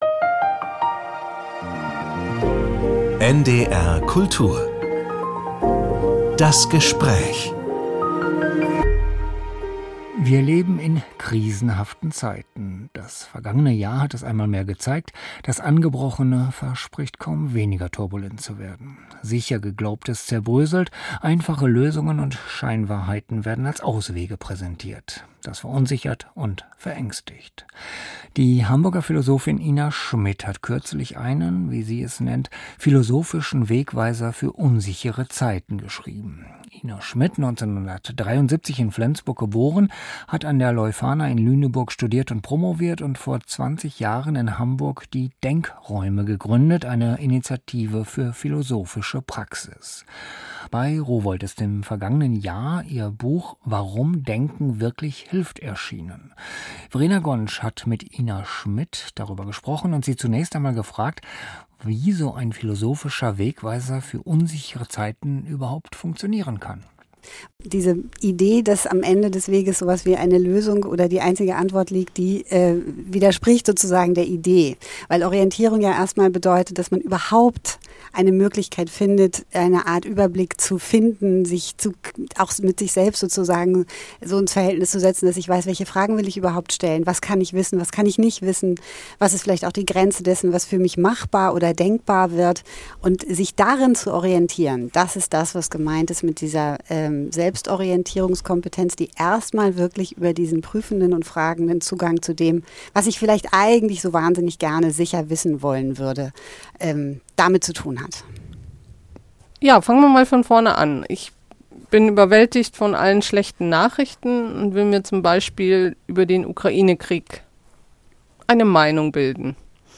NDR Kultur - Das Gespräch Podcast